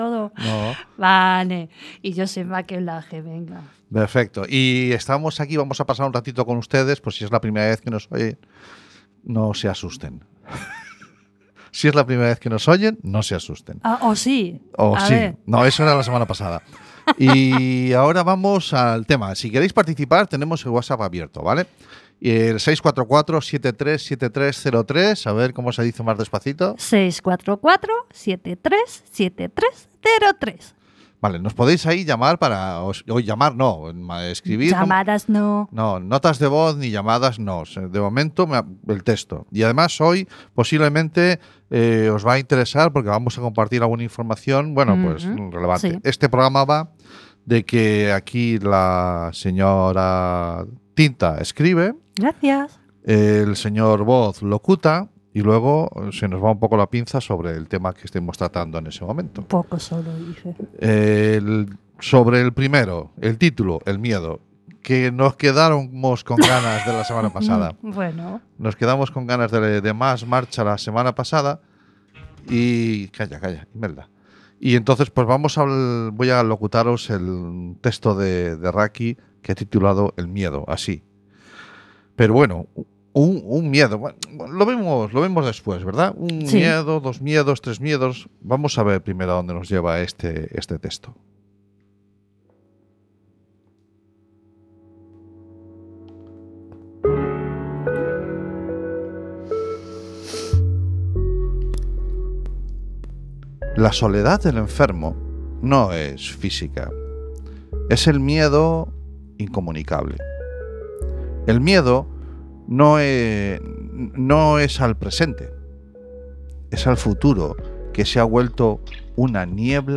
Deja que las palabras y la música te encuentren.